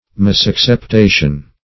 Search Result for " misacceptation" : The Collaborative International Dictionary of English v.0.48: Misacceptation \Mis*ac`cep*ta"tion\, n. Wrong acceptation; understanding in a wrong sense.